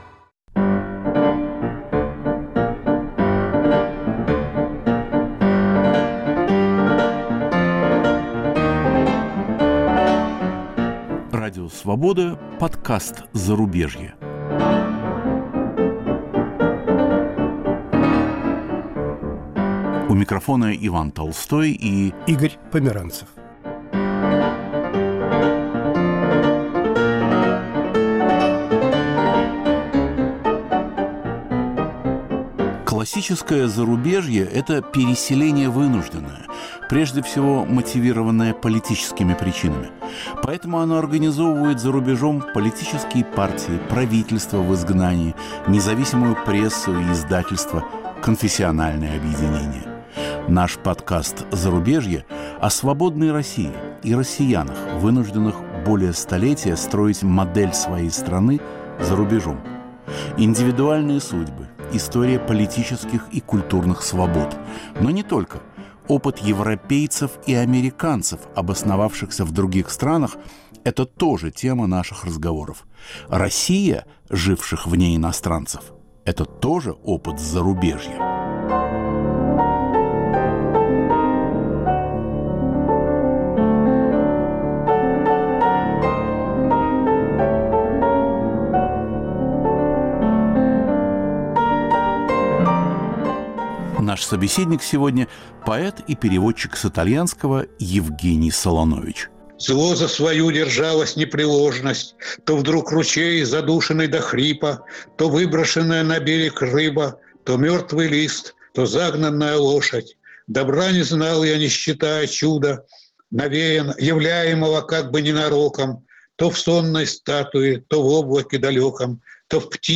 Наш собеседник сегодня – поэт и переводчик с итальянского Евгений Солонович